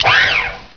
Sound Effects for Windows
robotarm.wav